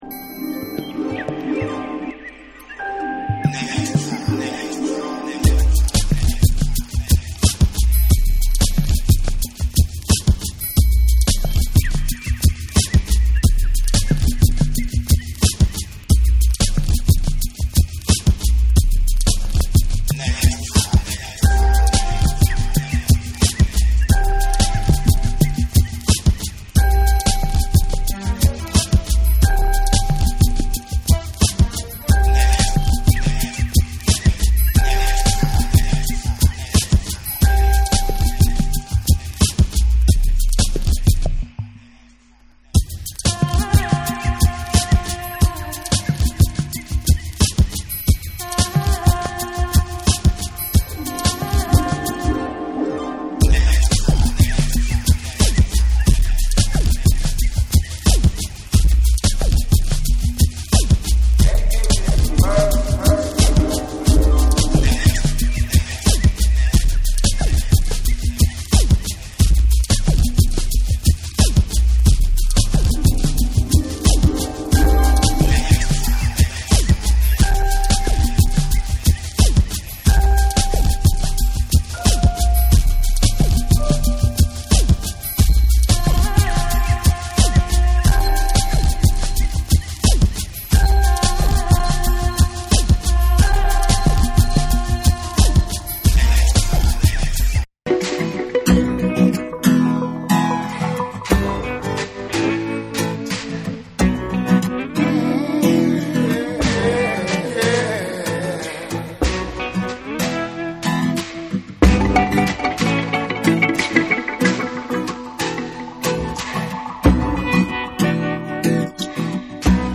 重心低くいパーカッシヴなリズムに民族調の弦楽器が混ざり合う1。
ダブ〜ブレイク〜ハウス〜民族音楽〜ファンクなど、様々な要素が入り交じった摩訶不思議な音世界を収録した大推薦盤。
BREAKBEATS